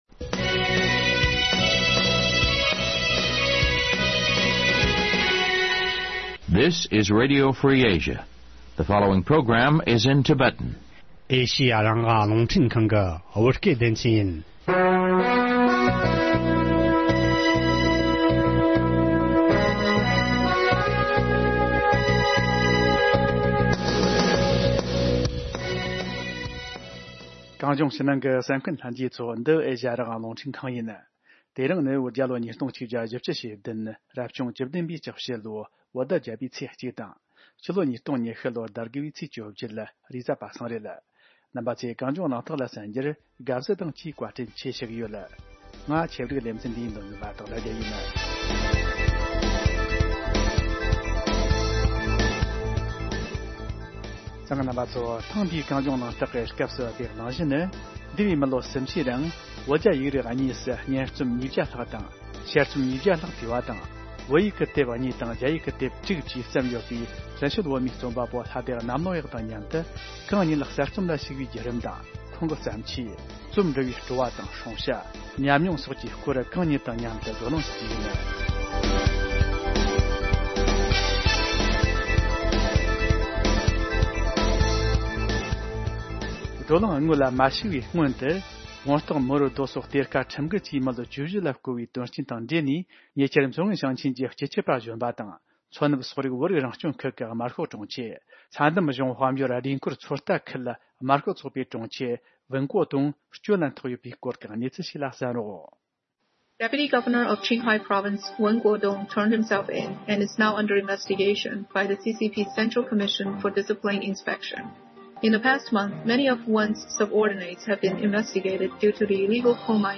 མཉམ་གླེང་བ།